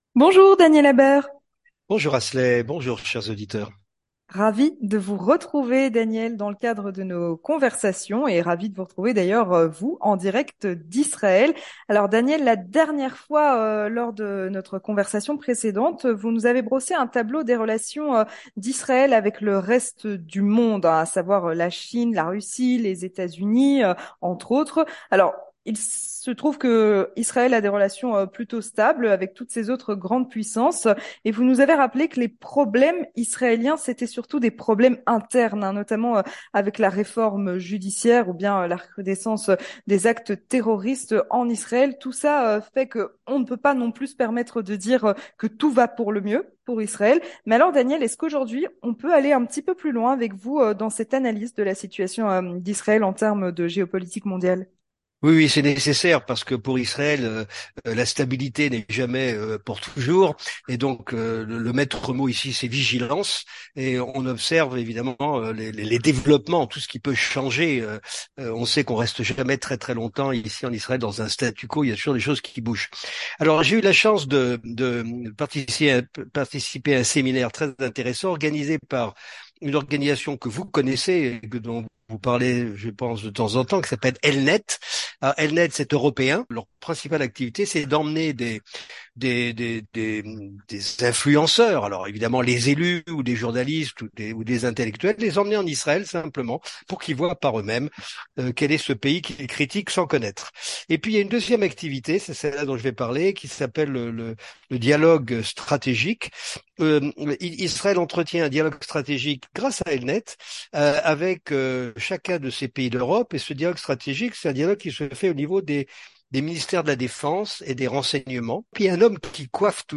Converstaion